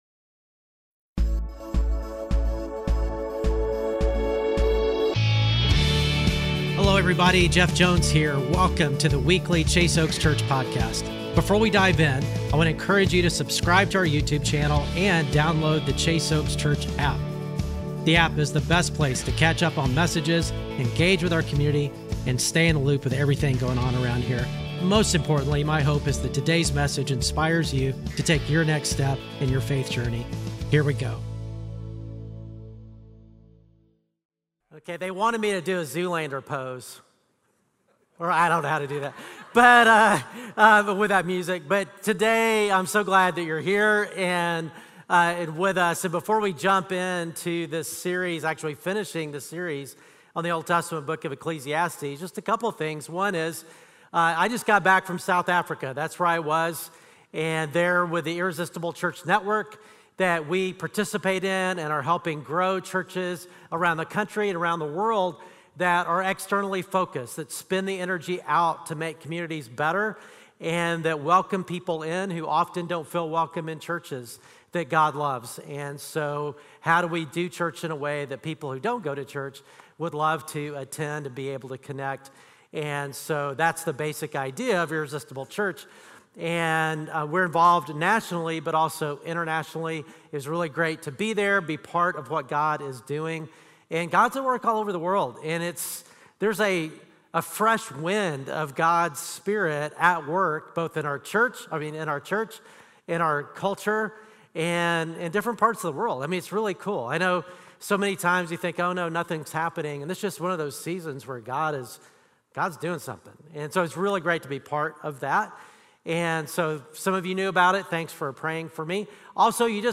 Weekly Sermons at Chase Oaks Church in Plano, Texas